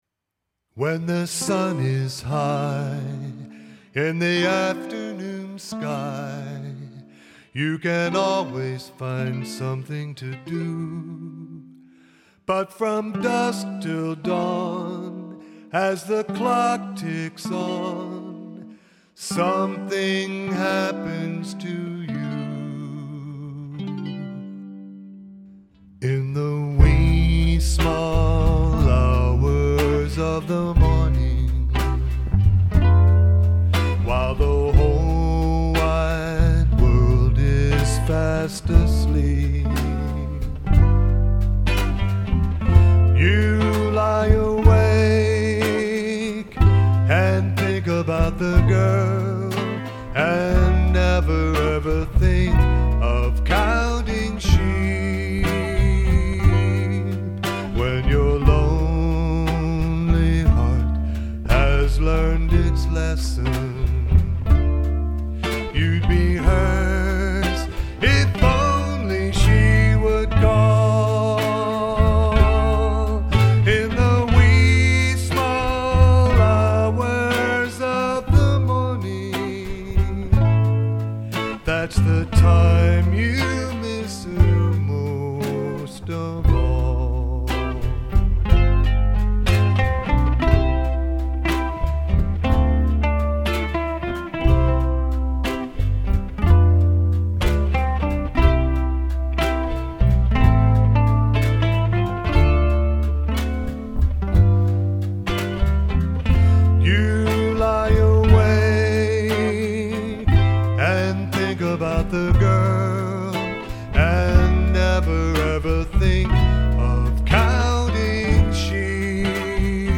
Wenn ich Leuten erzähle, dass ich elektrische Jazz-Ukulele spiele, können sie sich das nicht vorstellen.
Uke-Electric-Tenor